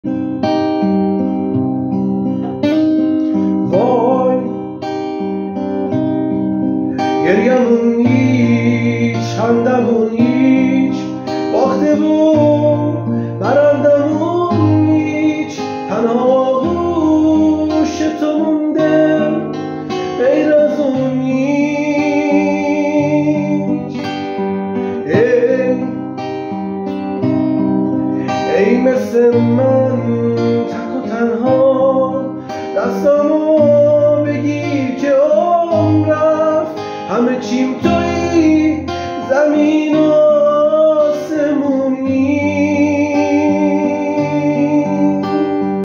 با گیتار